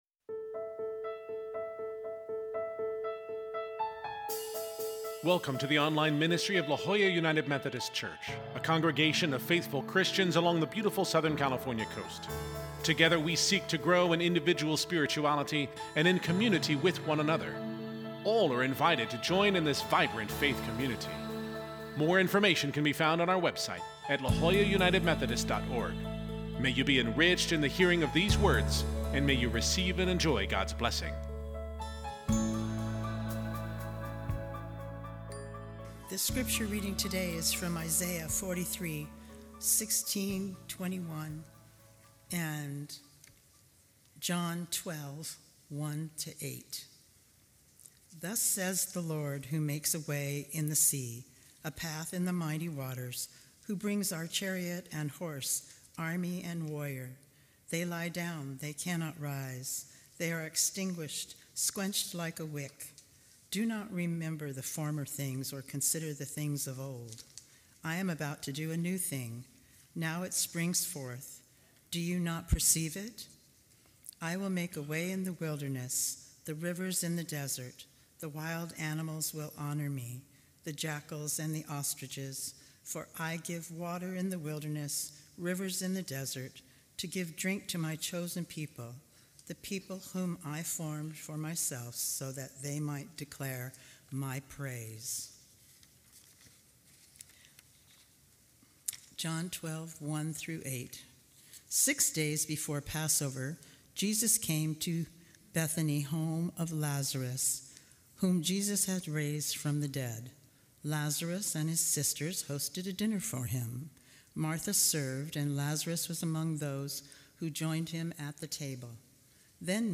The first 10 minutes of the embedded video has no sound.